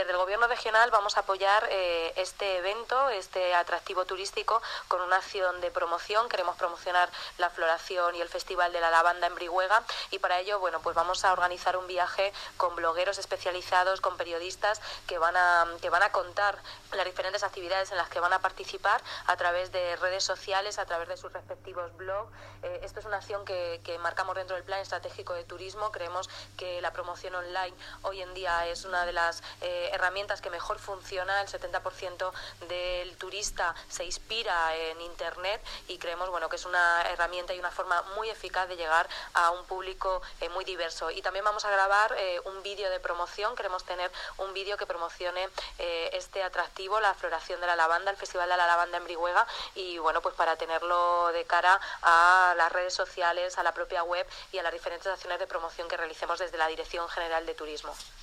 La directora general de Turismo, Ana Isabel Fernández Samper, habla del apoyo del Gobierno regional al Festival de la Lavanda de Brihuega.